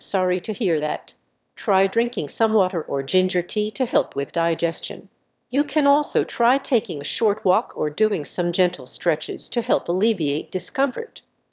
illness3_AirConditioner_2.wav